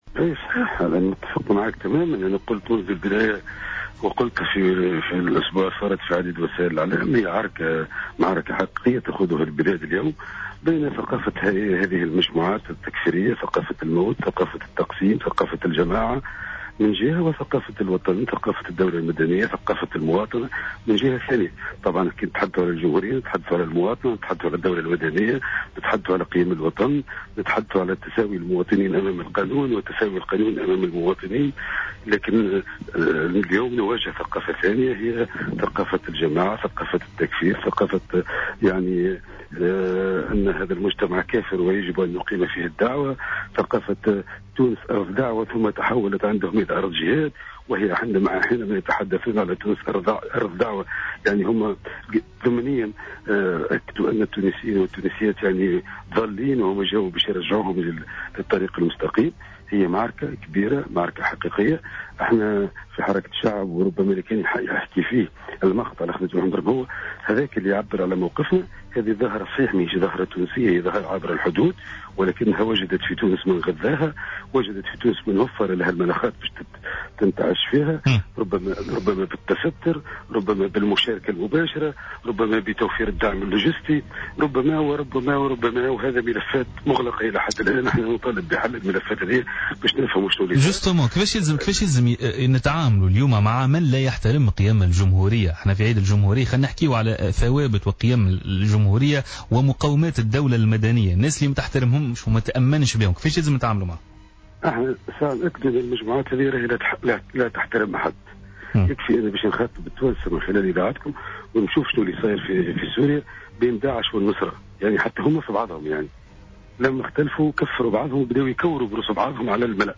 دعا الأمين العام لحركة الشعب،زهير المغزاوي اليوم في برنامج "بوليتيكا" على "جوهرة أف أم" إلى ضرورة التصدي للخطر الإرهابي ومقاومته بكل صرامة.